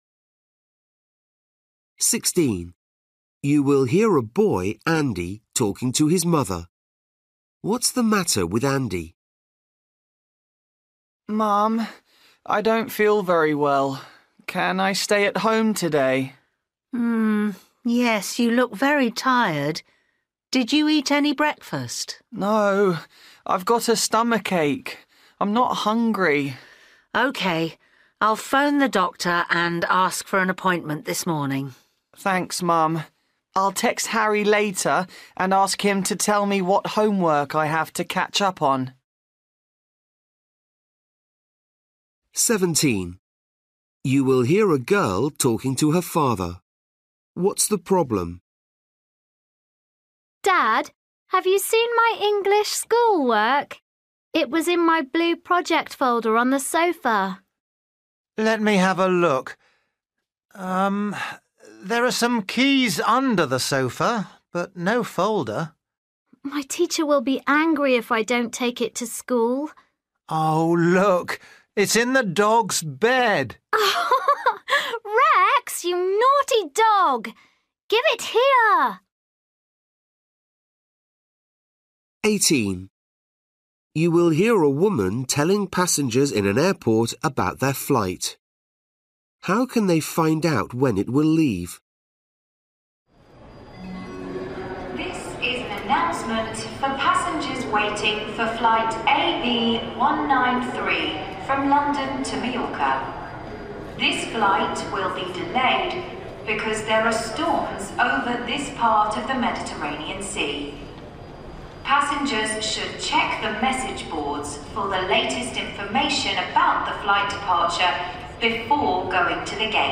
Listening: everyday short conversations
17   You will hear a girl talking to her father.
18   You will hear a woman telling passengers in an airport about their flight.